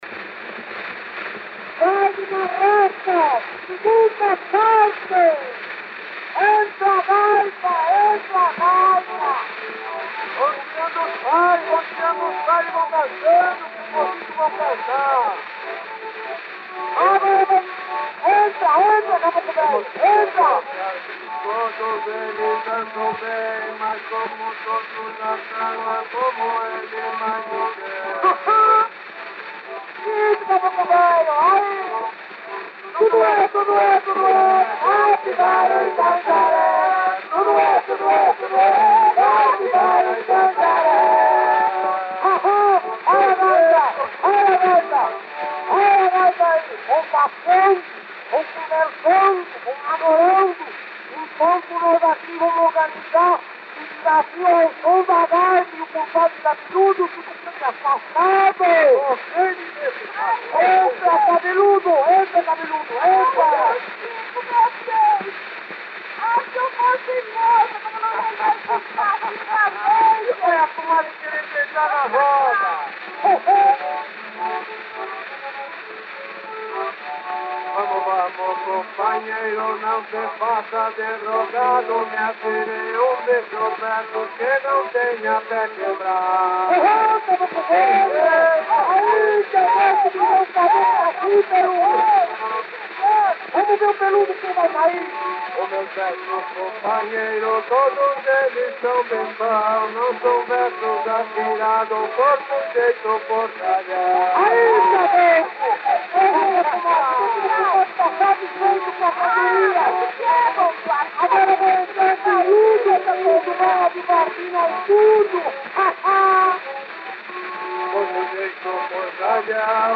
Disco de 78 rotações, também chamado "78 rpm", gravado em apenas um dos lados e com rótulo "tricolor".
O gênero foi descrito por meio do termo "cômica".
Gênero: Cômico.